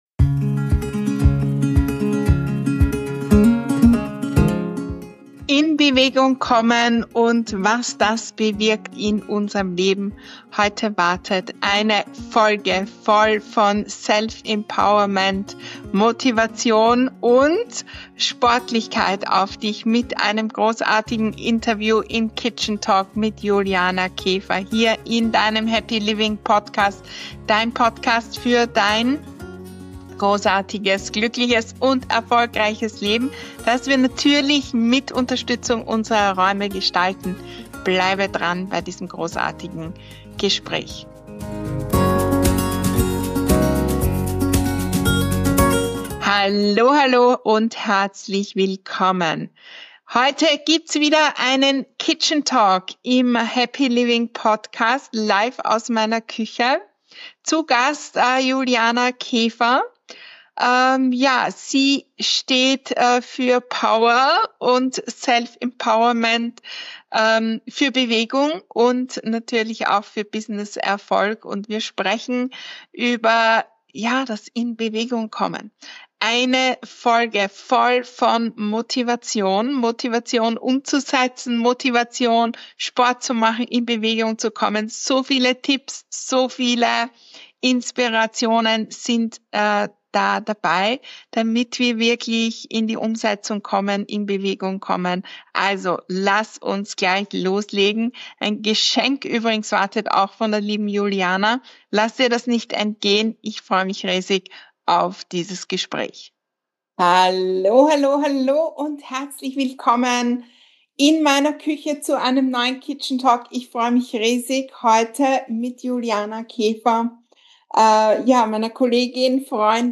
In dieser Folge des Happy Living Podcasts dreht sich alles um Bewegung, Motivation und Self-Empowerment. Im inspirierenden Gespräch